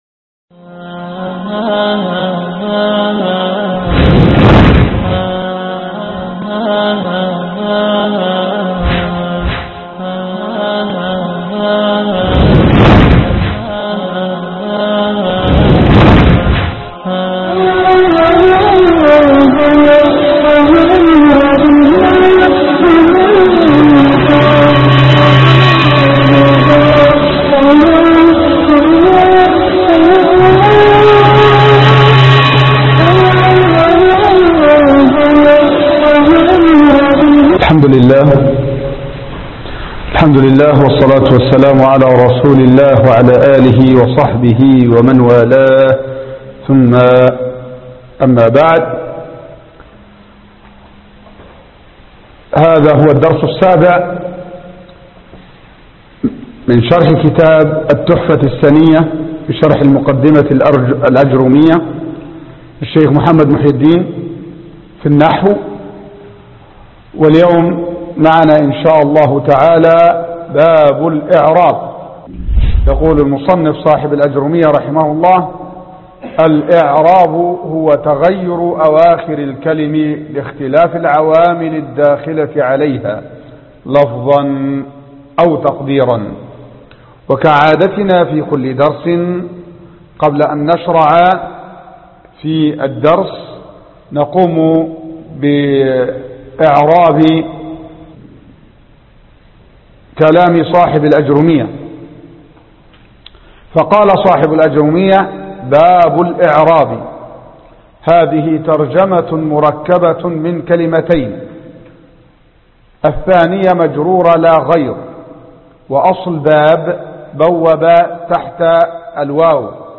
الدرس 7 باب الإعراب ( التحفة السنية شرح كتاب الآجرومية